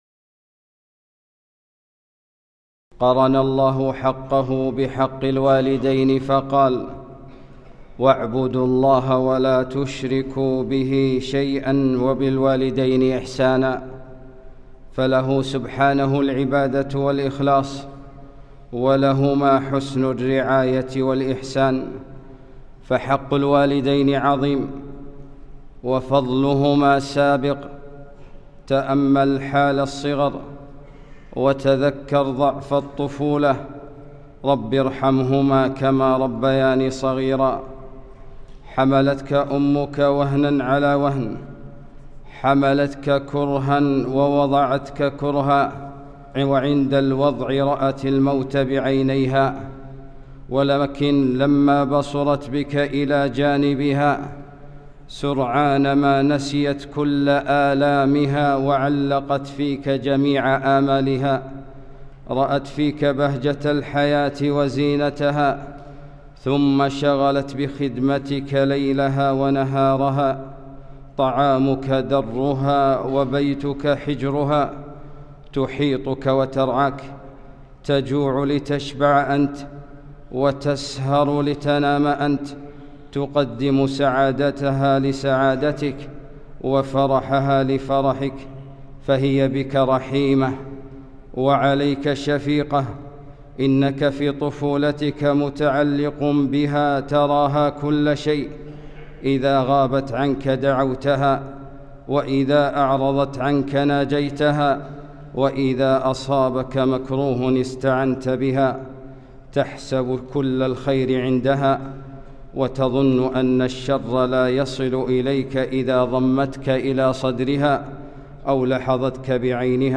خطبة - فضلُ الوالدين